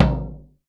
Acoustic Low Tom 01.wav